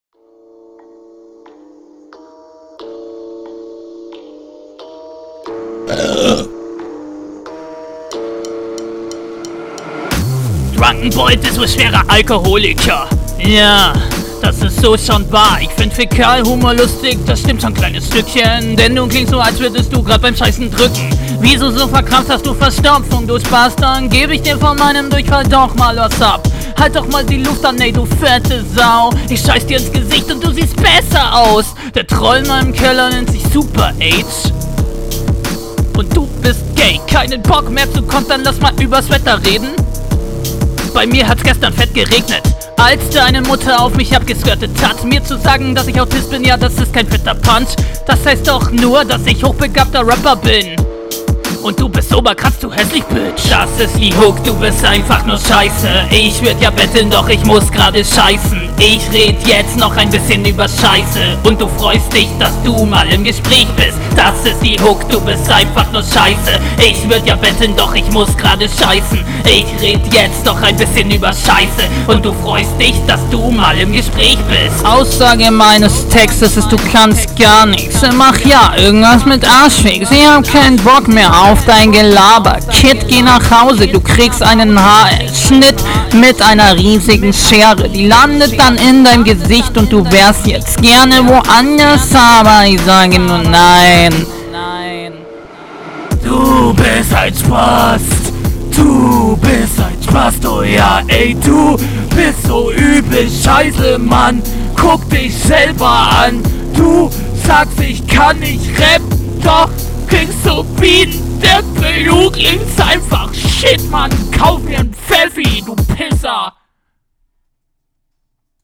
Soundqualität ist Aids durch die Übersteuerungen.